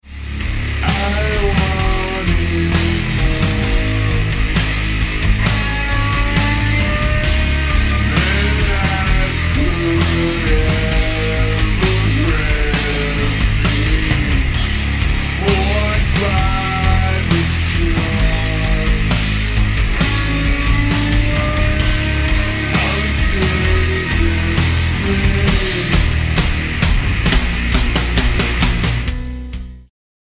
(slowed)